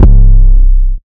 MURDA_808_DIOR_C.wav